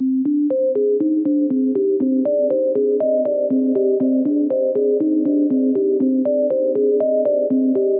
原声/鼓的过滤循环120BPM in 4/4
描述：在4/4的120BPM随机循环，使用震动鼓和声学吉他穿过阶梯滤波器 单声道到立体声/混响